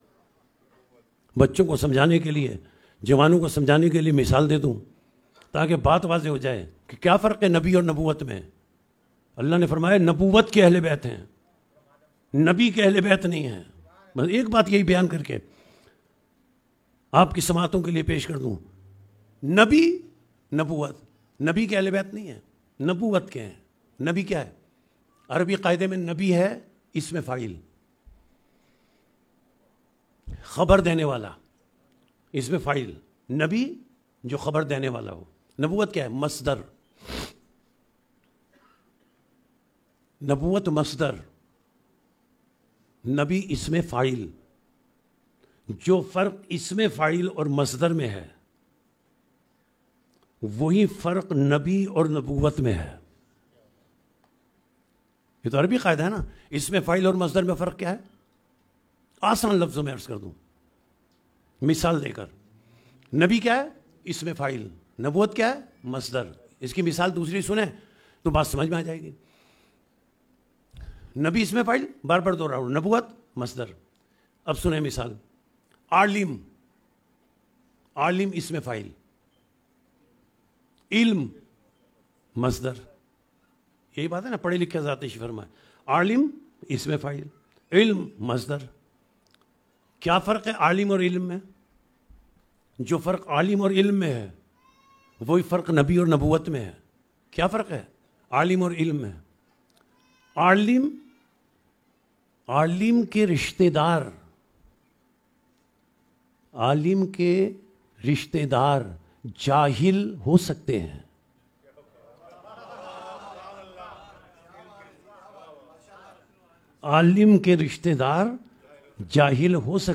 اس پوڈکاسٹ میں نہایت خوبصورت انداز بیان کے ساتھ "اہل بیت نبوت” کا تعارف بیان کیا گیا ہے، جسے ہر عمر کے افراد آسانی سے سمجھ سکتے ہیں کہ نبوت کے اہل بیت اور نبی کے اہل بیت میں فرق کیا ہے۔